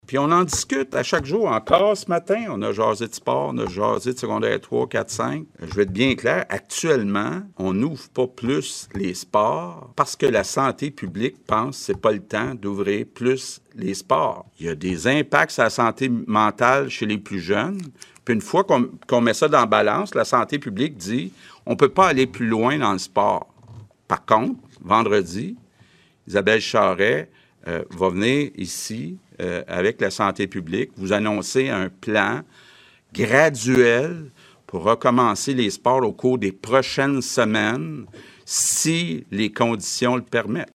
Le gouvernement du Québec a confirmé cet après-midi en point de presse que la reprise des sports n’est pas au scénario pour l’instant.
Voici les propos du 1er ministre :